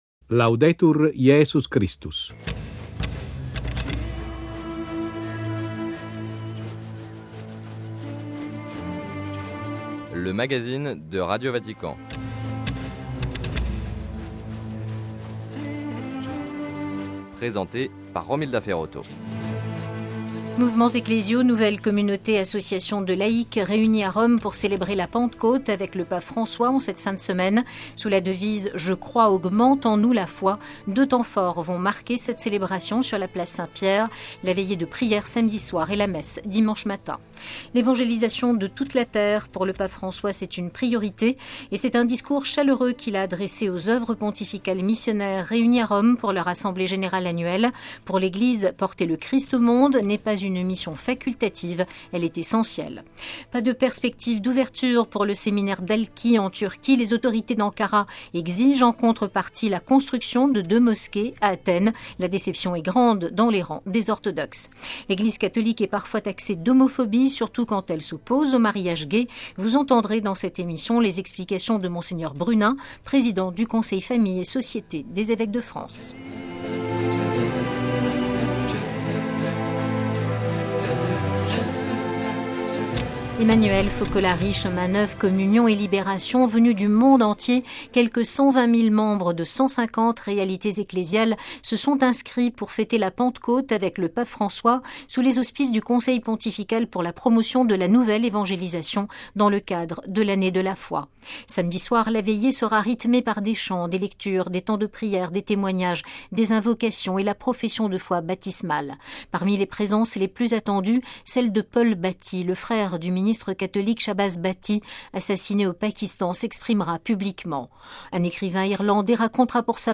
Sommaire : - Mouvements ecclésiaux, nouvelles communautés et associations de laïcs célèbrent la Pentecôte avec le pape François à Rome. Entretien